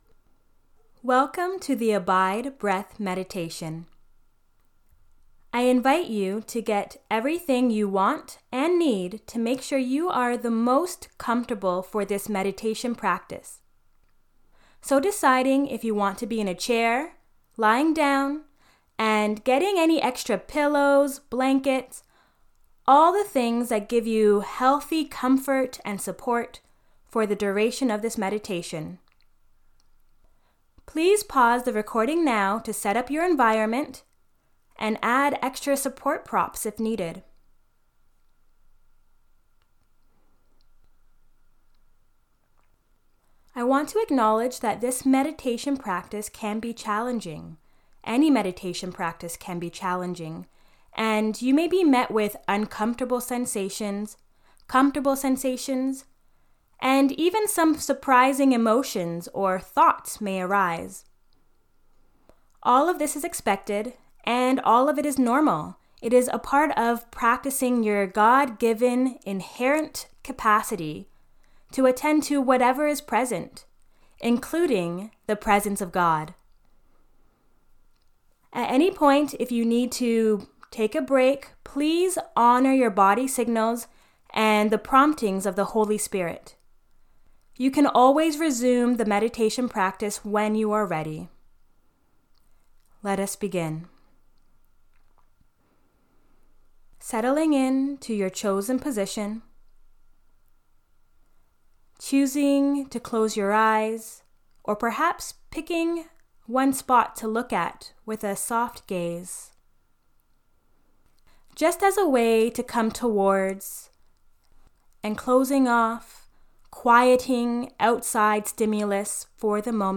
Christian-Breath-Meditation-Abide.mp3